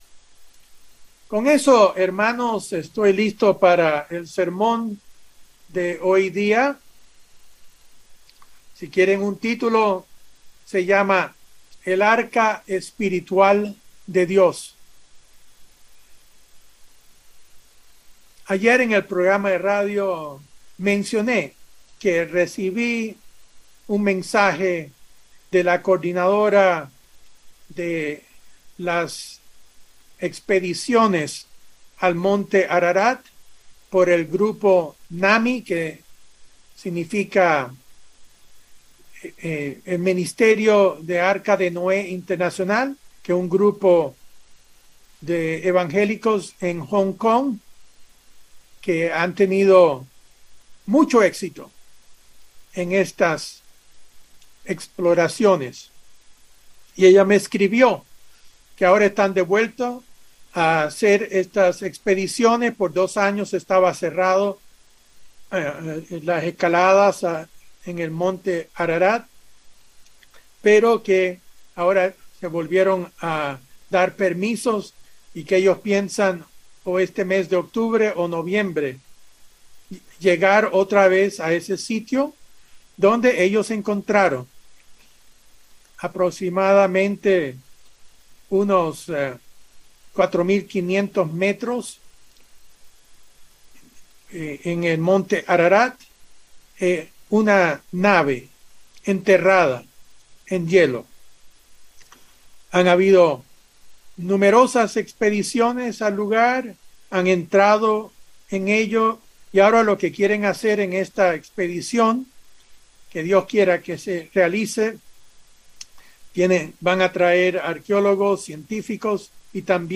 Mensaje entregado el 16 de octubre de 2021.